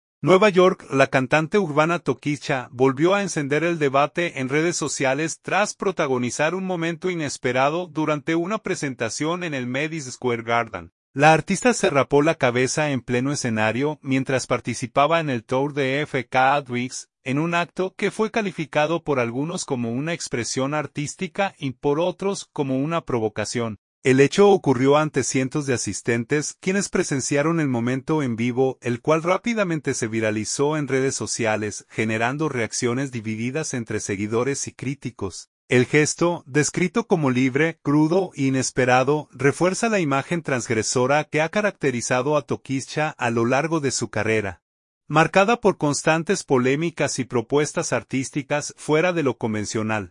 NUEVA YORK.- La cantante urbana Tokischa volvió a encender el debate en redes sociales tras protagonizar un momento inesperado durante una presentación en el Madison Square Garden.
El hecho ocurrió ante cientos de asistentes, quienes presenciaron el momento en vivo, el cual rápidamente se viralizó en redes sociales, generando reacciones divididas entre seguidores y críticos.